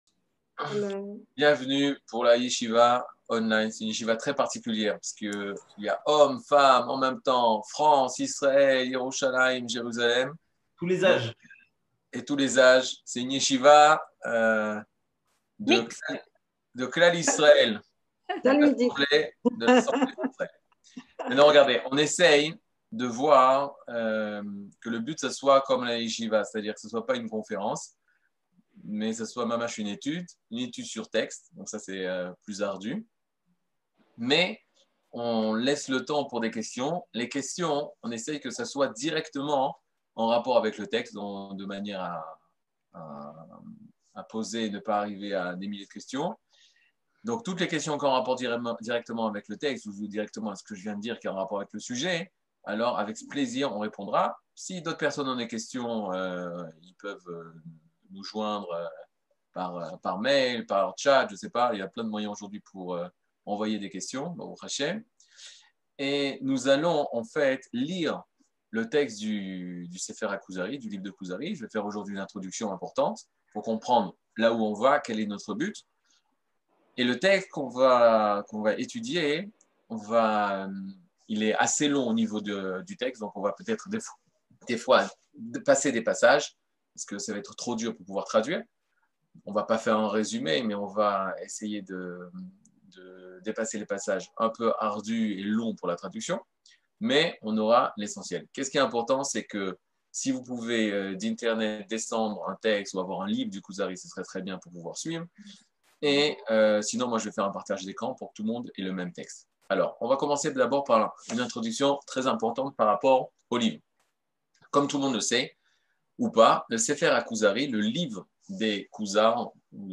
Catégorie Le livre du Kuzari partie 1 00:57:46 Le livre du Kuzari partie 1 cours du 16 mai 2022 57MIN Télécharger AUDIO MP3 (52.88 Mo) Télécharger VIDEO MP4 (124.63 Mo) TAGS : Mini-cours Voir aussi ?